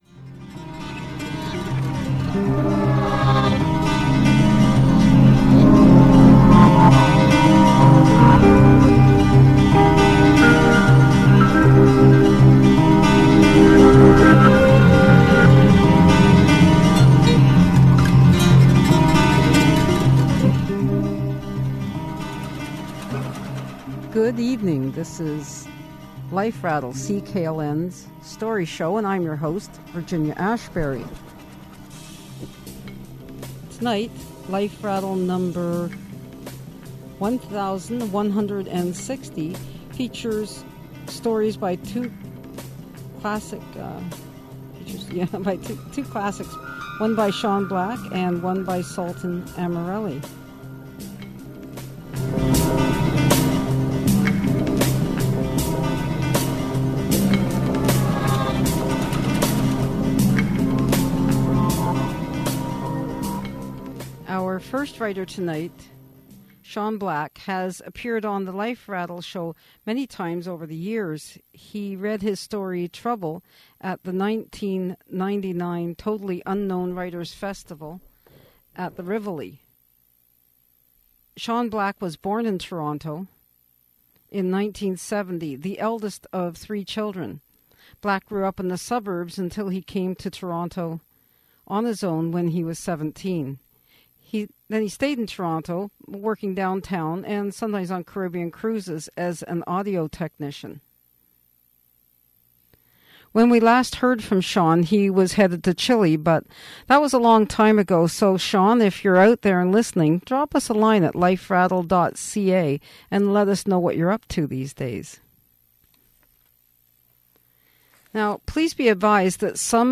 aired on CKLN Sunday, July 10, 2011